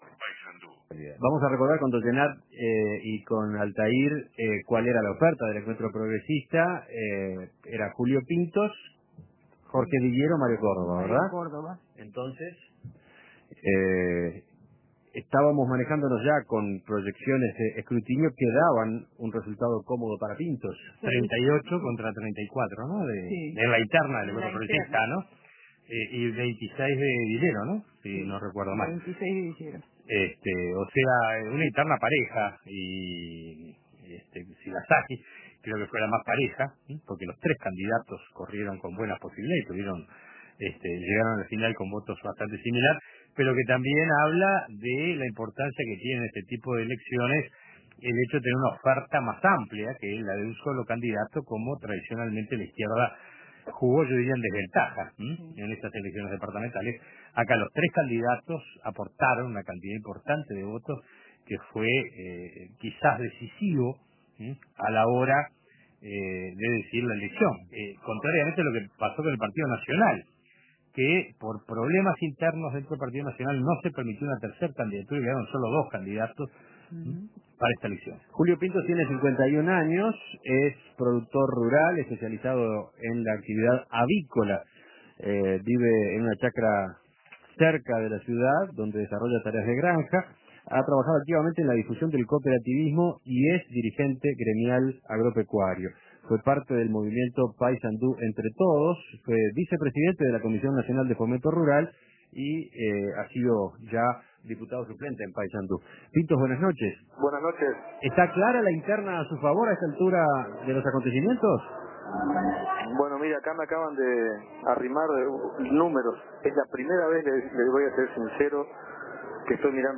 Entrevistas Paysandú: Un duro golpe al Partido Nacional Imprimir A- A A+ Julio Pintos, productor rural y dirigente gremial agropecuario, ganó en uno de los bastiones blancos.